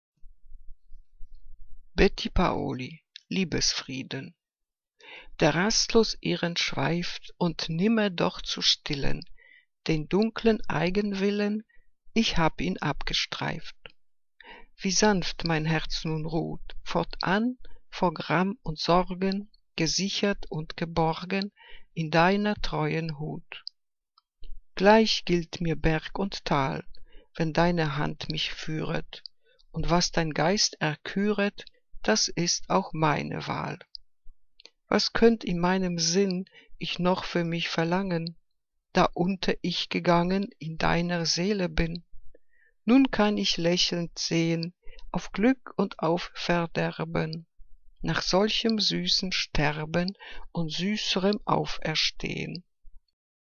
Ausgewählte Liebesgedichte